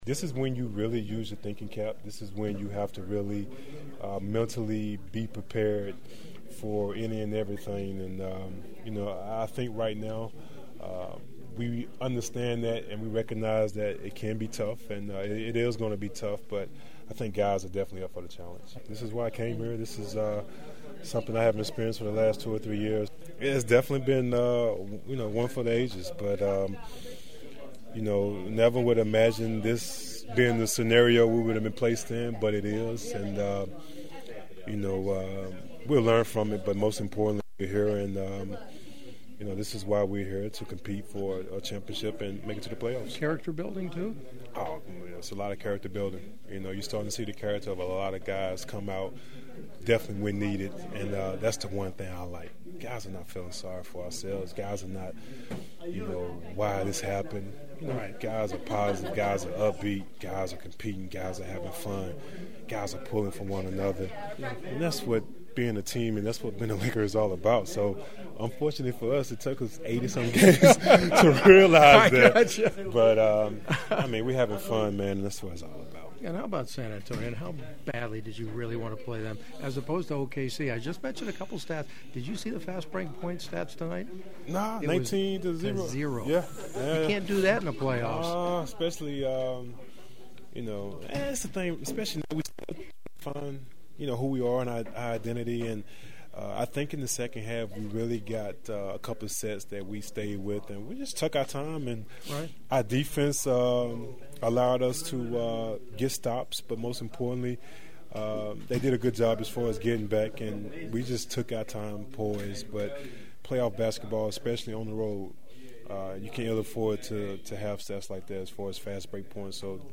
I had some great postgame locker room chats that unfortunately I won’t be able to share with you since they were messed up by a microphone malfunction.